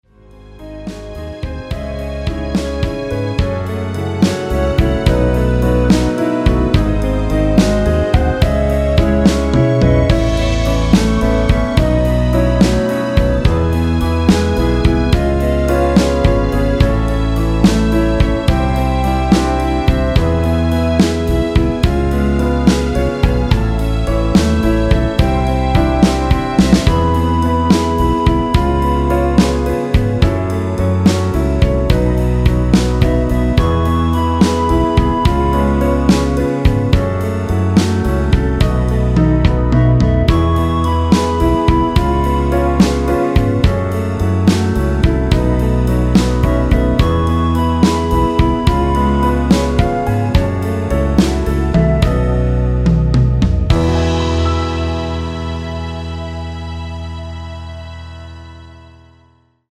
엔딩이 페이드 아웃이라 노래하기 편하게 엔딩을 만들어 놓았습니다.
원키 멜로디 포함된 MR입니다.(미리듣기 확인)
앞부분30초, 뒷부분30초씩 편집해서 올려 드리고 있습니다.
중간에 음이 끈어지고 다시 나오는 이유는